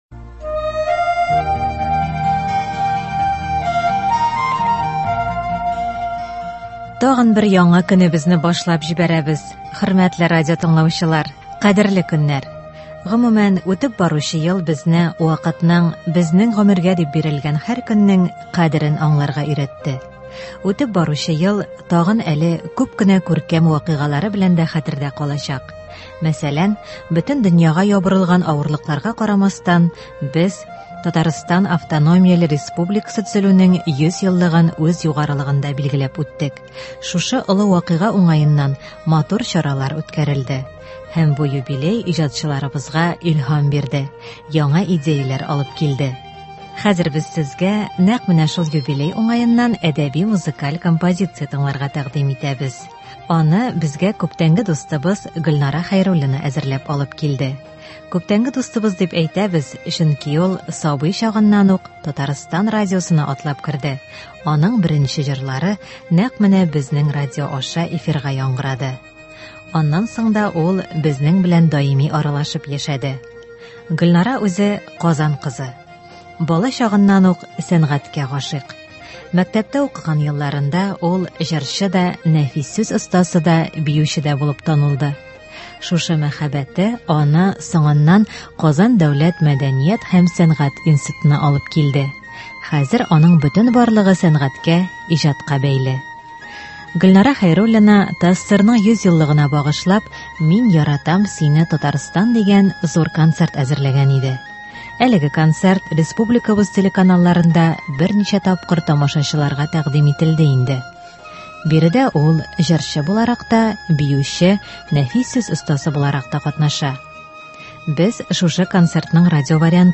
Әдәби-музыкаль композиция (26.12.20)